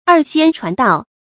二仙传道 èr xiān chuán dào
二仙传道发音